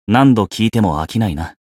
觉醒语音 不管听多少次都不会厌倦 何度聴いても飽きないな 媒体文件:missionchara_voice_562.mp3